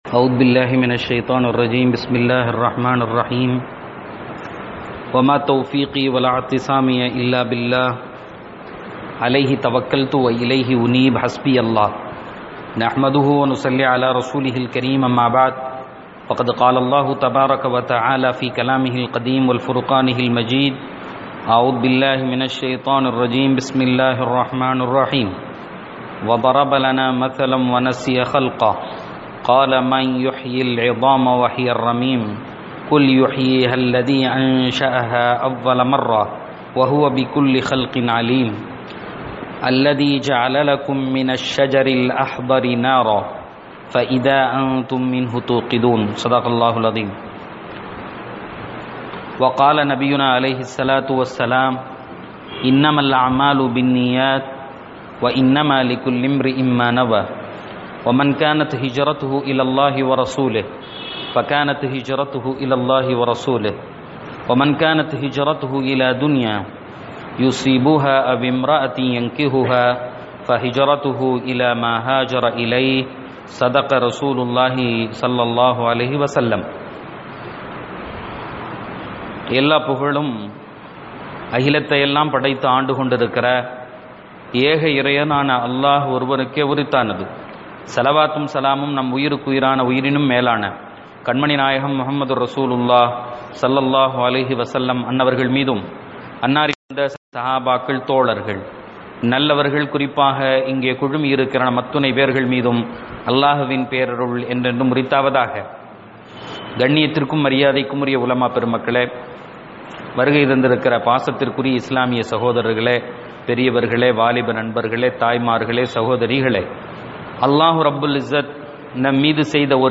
யாஸீன் விளக்கவுரை: ஆயத்: 79-80 (18-Mar-2018) 36:79.